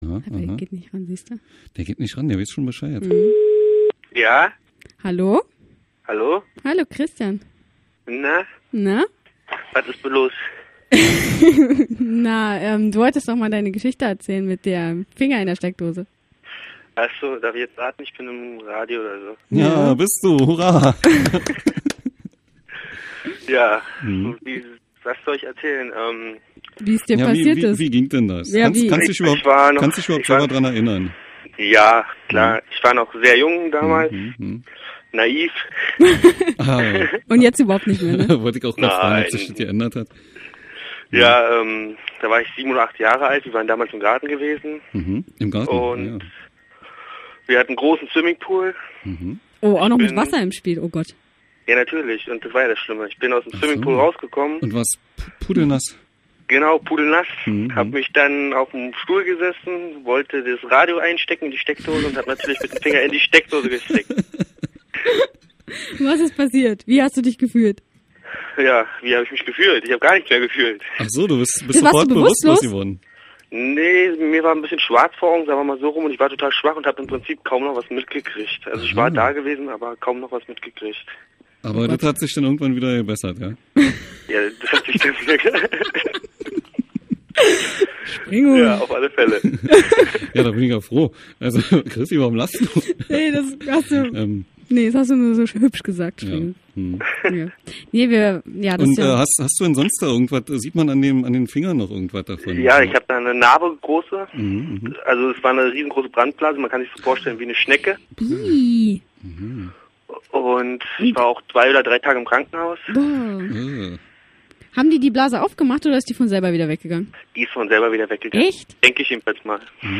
Wir waren uns einig in der Ablehnung von Spendenbettelei mittels zugesteckter schlechter Schlager-CDs und wir telefonierten mit einem Original Stromschlag-Opfer!
stromschlag.mp3